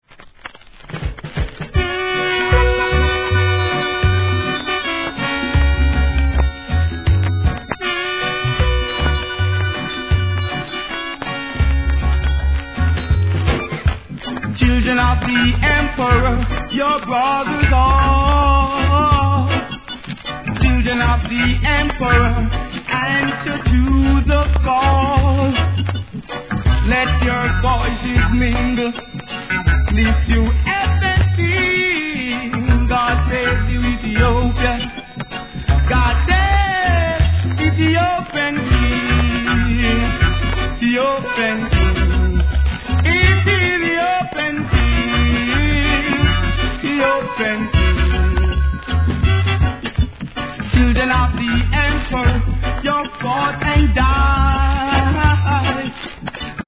REGGAE
味のあるこのヴォーカルでの怒渋ROOTSの決定盤!!